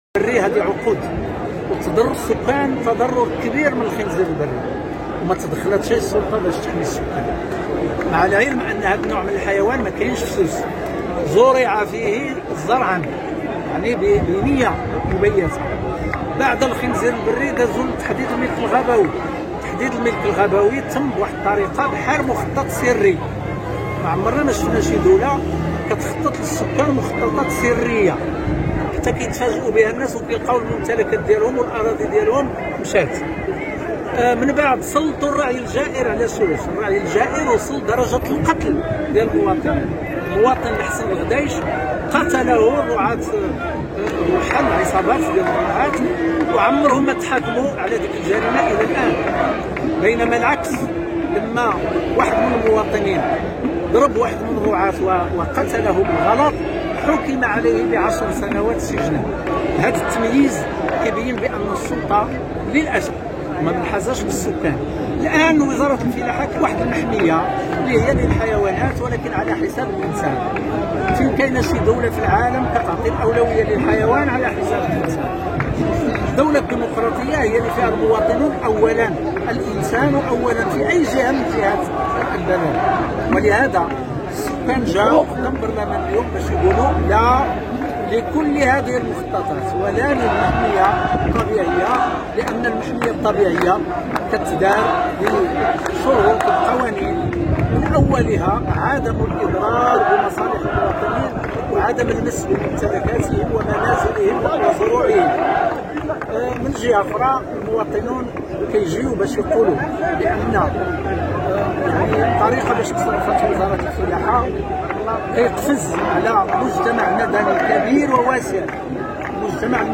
Een woordvoerder van de bewoners zegt dat mensen die zich tegen de agressie van de nomaden verdedigen opgepakt en veroordeeld worden maar de nomaden worden vrijgelaten.